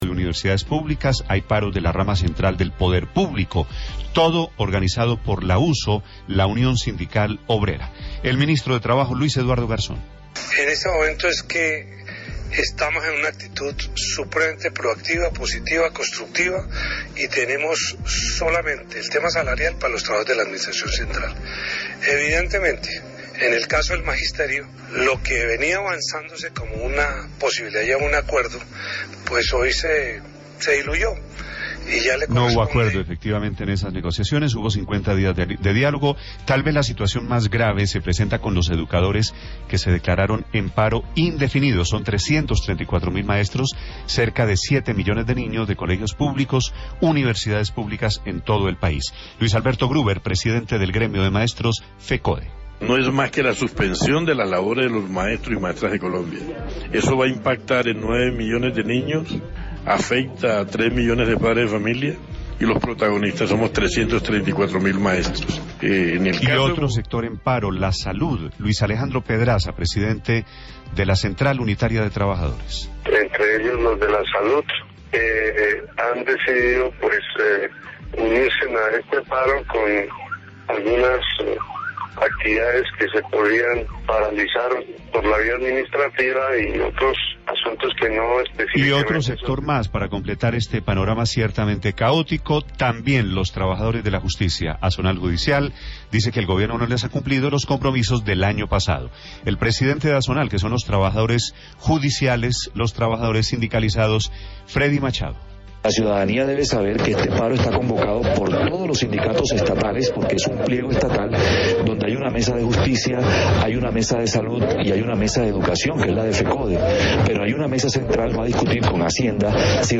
Grabado en la madrugada del dia 22 de abril, a través de Blu Radio.